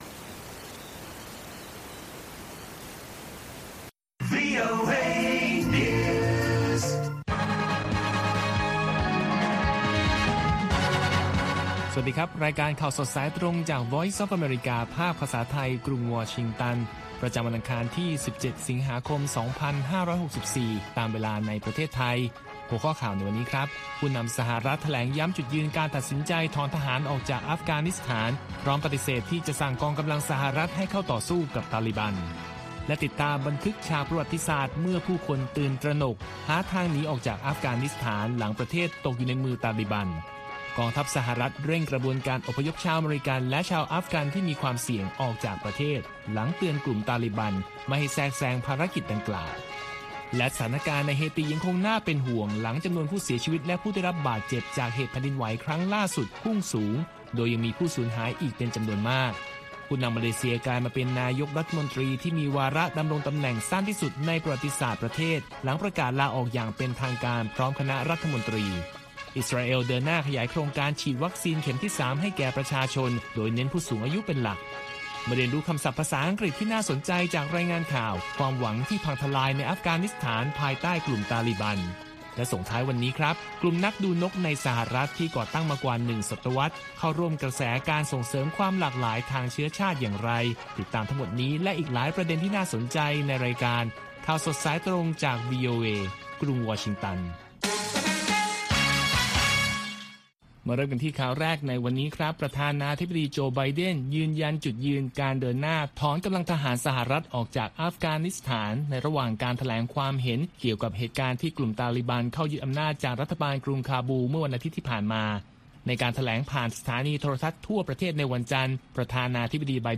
ข่าวสดสายตรงจากวีโอเอ ภาคภาษาไทย ประจำวันอังคารที่ 17 สิงหาคม 2564 ตามเวลาประเทศไทย